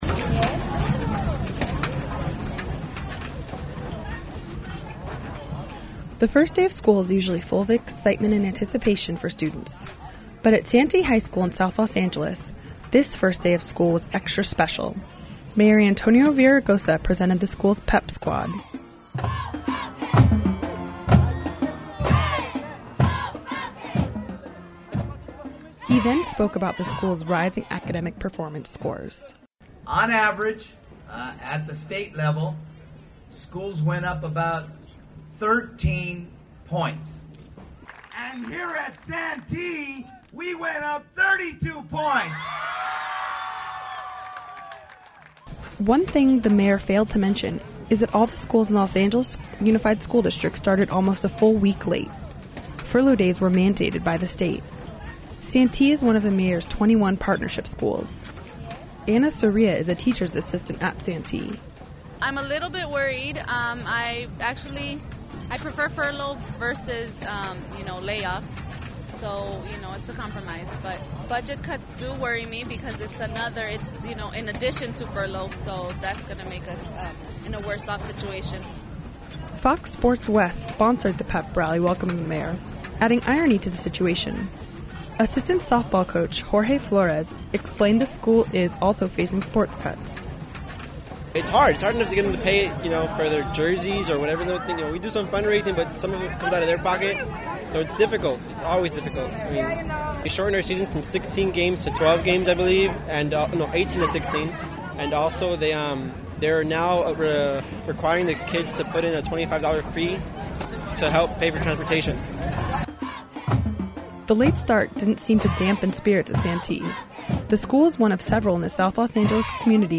The Mayor of Los Angeles introduced of the pep squad at Santee High School for the first day of school.
peprallywithmayor.mp3